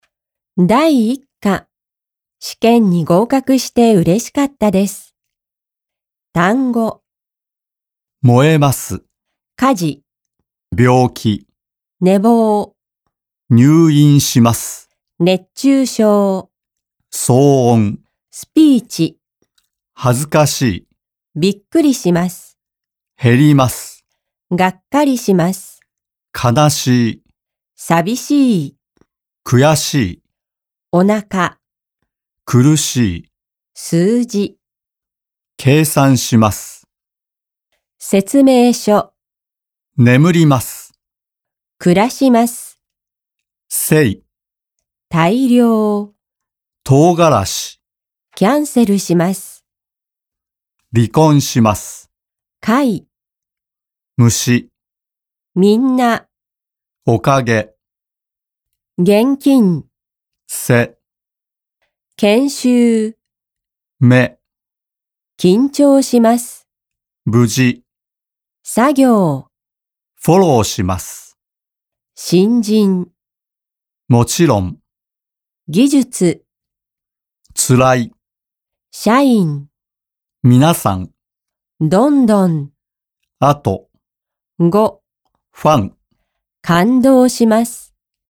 全文錄音：附贈錄全文朗讀內容，不僅收錄單字、會話、練習問題的聽力習題，更重要的還收錄所有句型的套入練習，只要反覆聆聽，即可輕鬆練就一副日文耳。